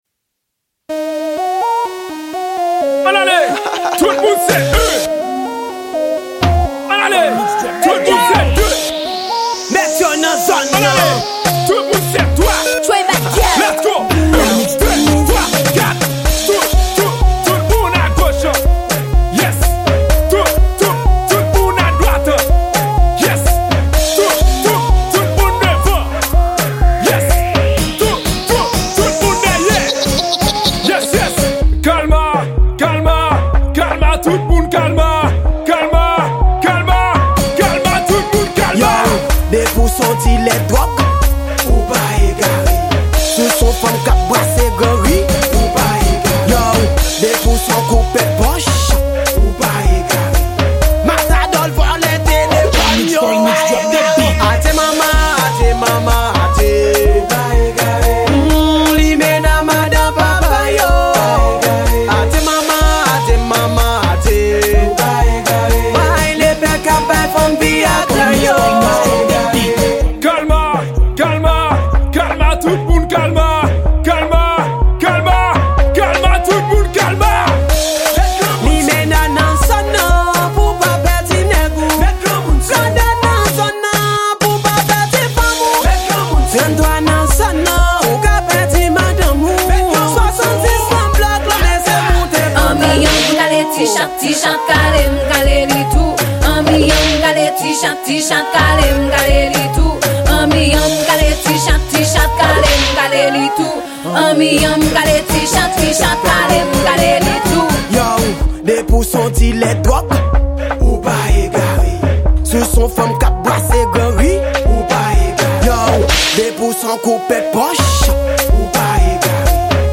Genre: Mix